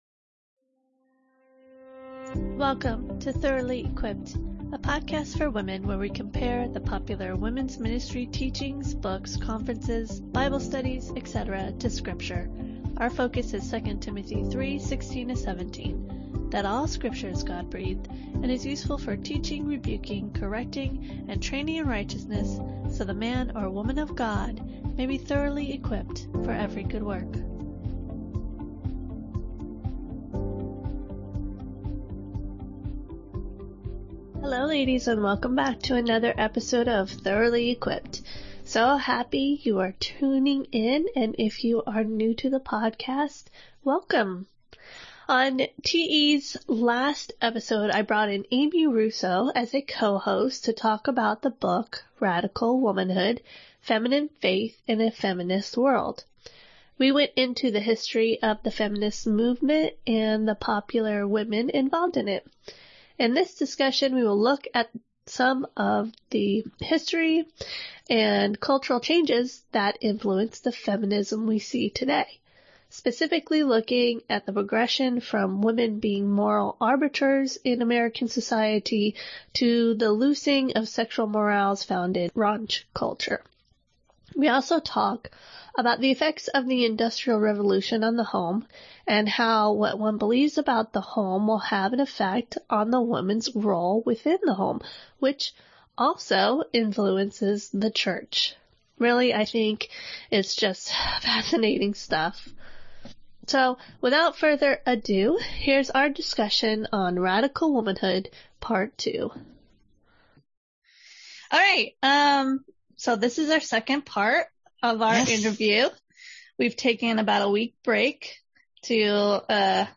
Titus 2 Time- A “Radical Womanhood” discussion